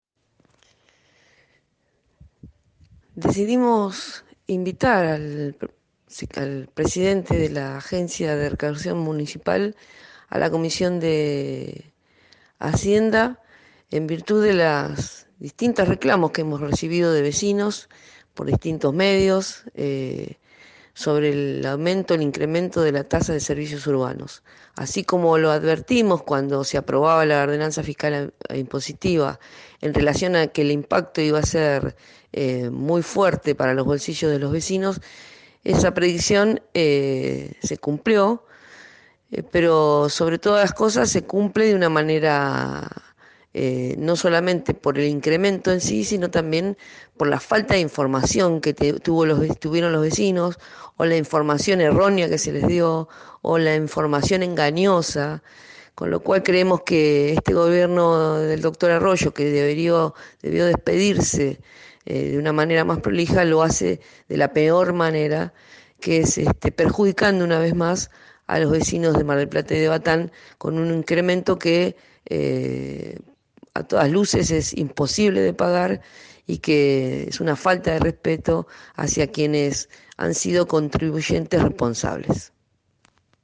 La integrante de la Comisión de Hacienda dijo a Bien Despiertos, programa emitido de 7 a 9, por Radio De la Azotea: “Por ello, invitamos a participar de la reunión de Hacienda del Concejo Deliberante al titular de la Agencia de Recaudación Municipal (ARM), Mariano Correa, a dar explicaciones”.